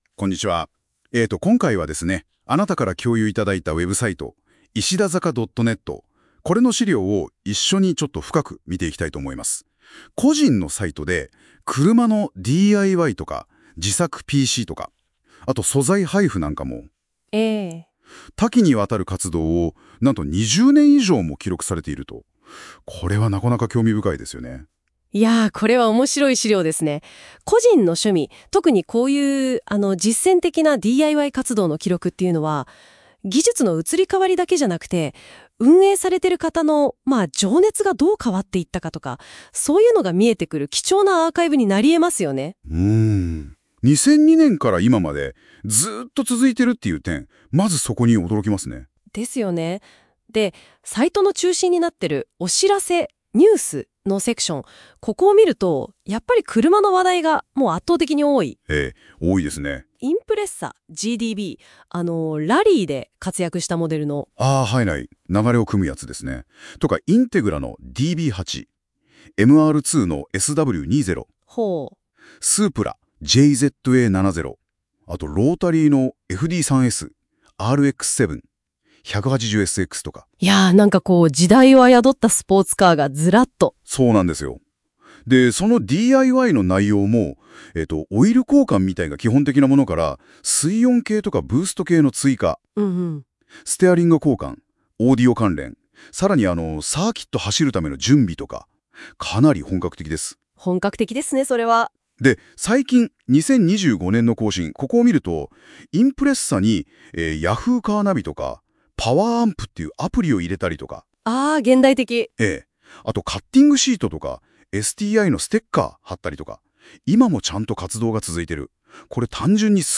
NotebookLM音声解説